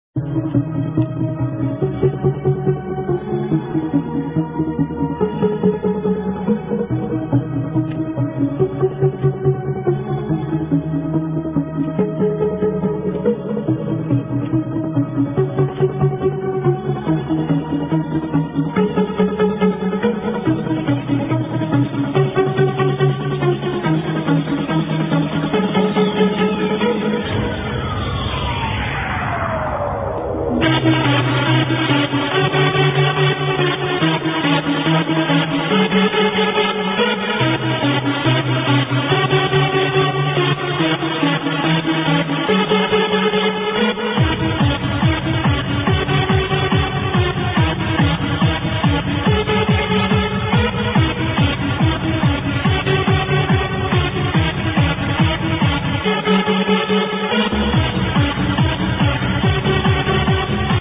banging hardtrance tune. with sample. please help id.